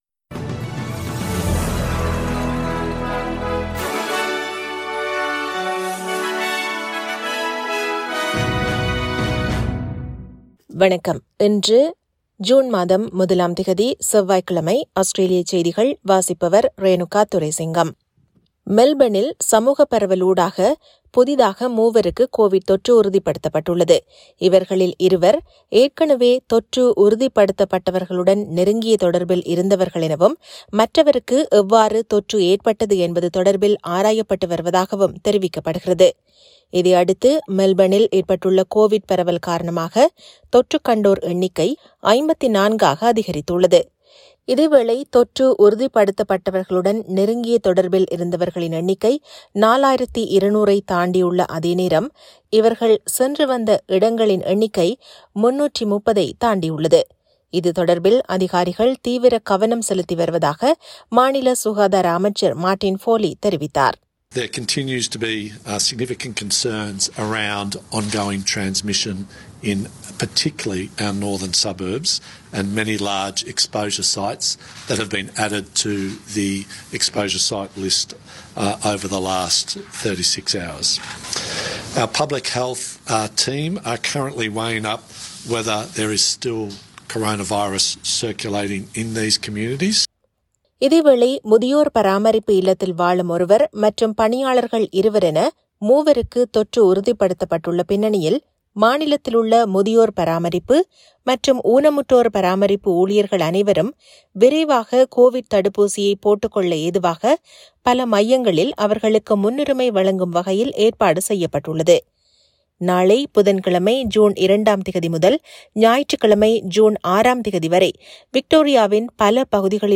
Australian news bulletin for Tuesday 01 June 2021.